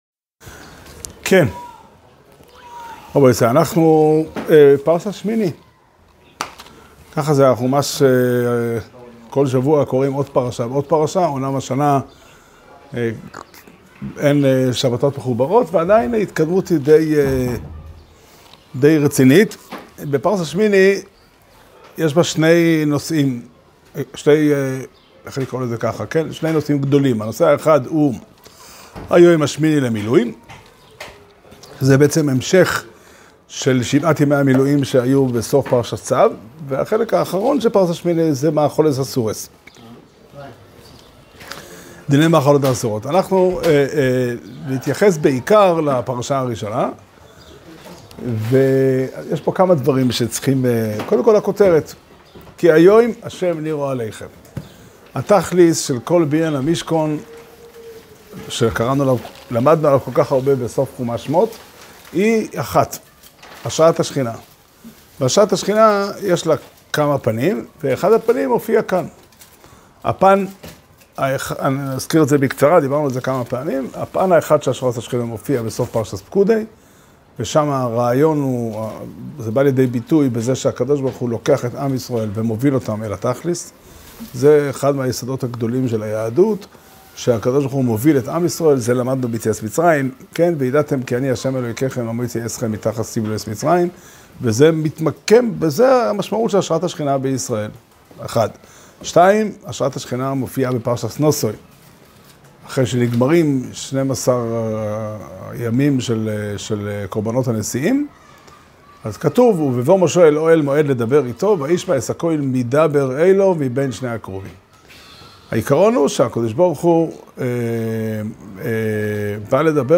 שיעור שנמסר השבוע בבית המדרש 'פתחי עולם' בתאריך כ"א אדר ב' תשפ"ד